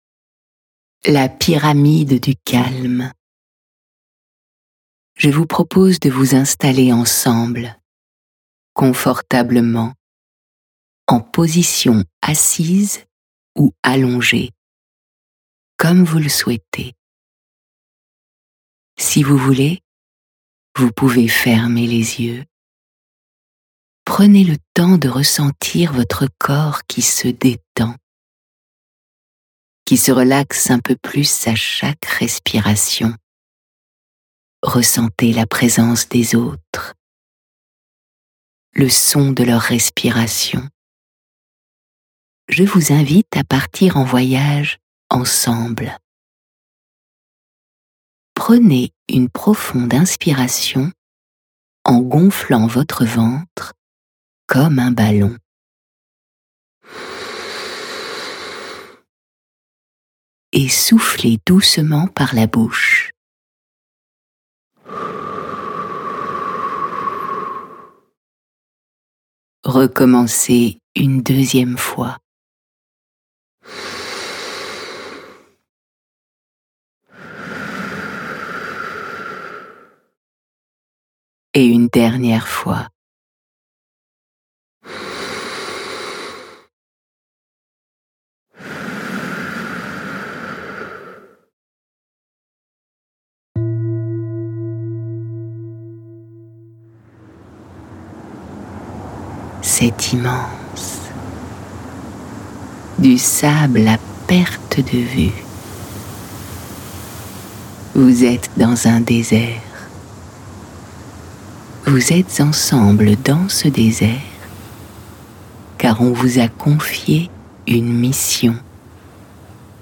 MED_PLUSIEURS_DESERT_extrait.mp3